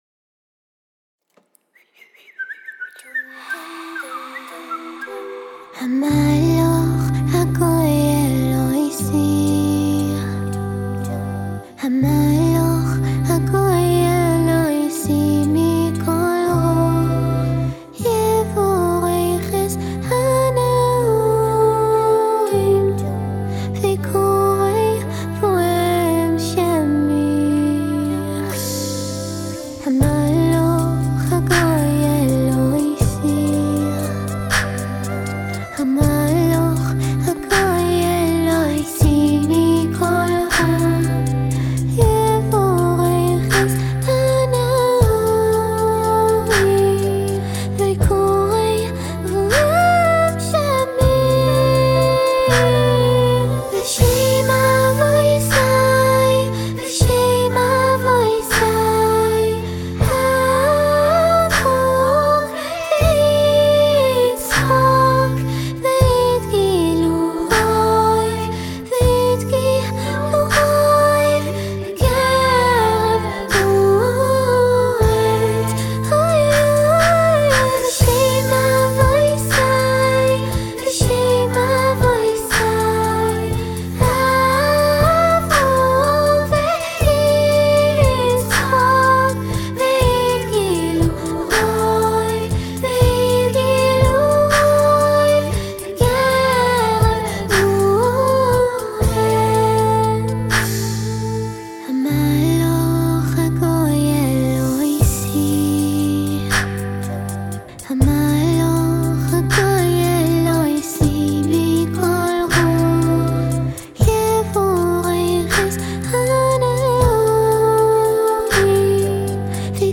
מאז ומעולם אהבתי הרמוניות ומקהלות, זה היה נשמע לי קסום, גן עדן של קולות…
כשהחלטתי ליצור את השיר הואקאלי הראשון שלי, חיפשתי את השיר שיתיישב הכי טוב עם החלום הרטוב…